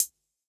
Index of /musicradar/ultimate-hihat-samples/Hits/ElectroHat D
UHH_ElectroHatD_Hit-28.wav